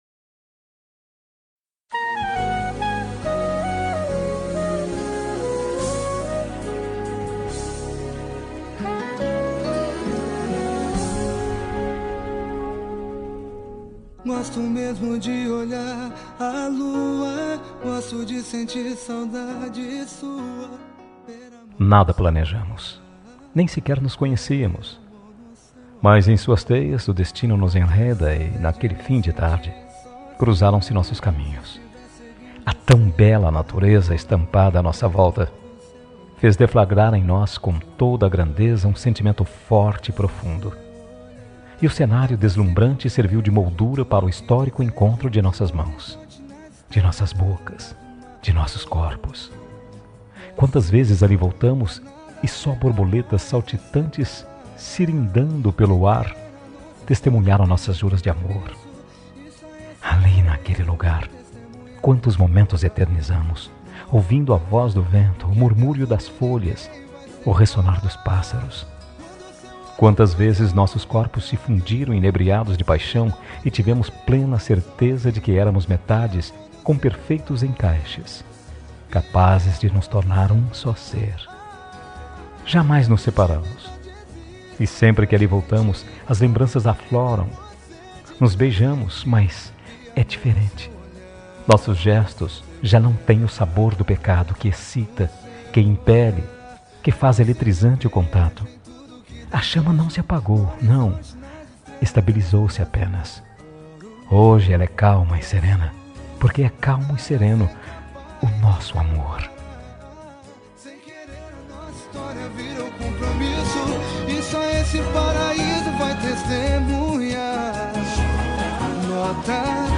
Telemensagem Romântica para Ex. Voz Masculina – Cód: 201679